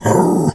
pgs/Assets/Audio/Animal_Impersonations/dog_2_growl_01.wav at master
dog_2_growl_01.wav